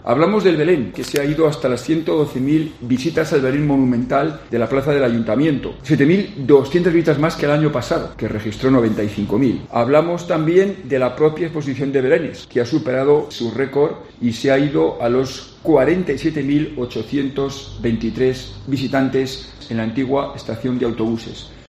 Miguel Sáinz, concejal de Promoción de la Ciudad